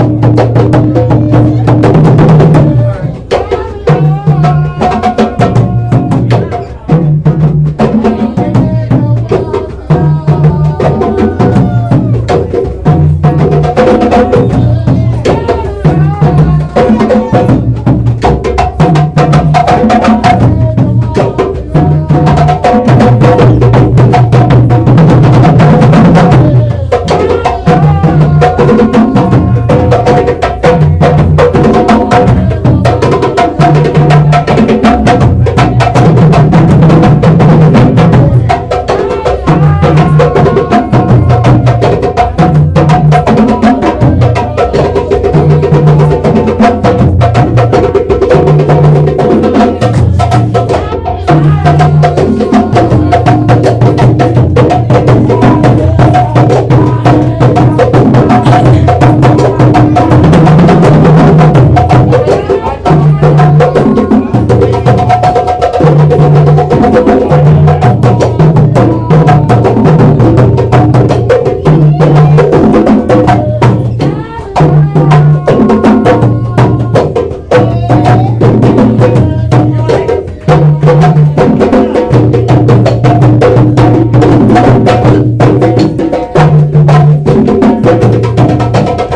Live drummers
62890-live-drummers.mp3